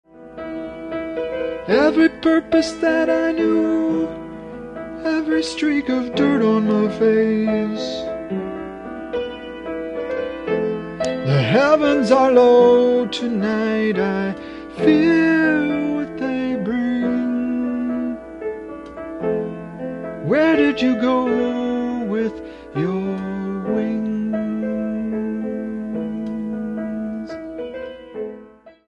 Recorded direct to cassette in Richmond Beach.
Binaural recordings